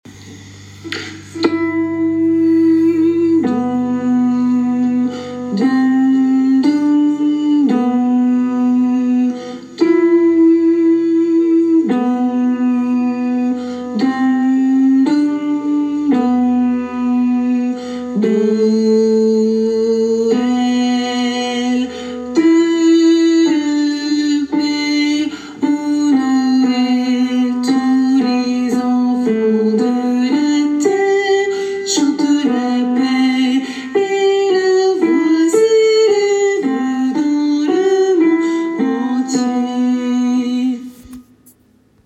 hommes et autres voix en arrière plan